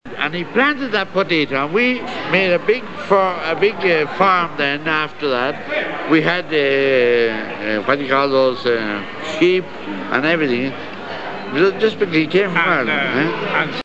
Dinner at the Hurling Club of Buenos Aires, 25 May 1987,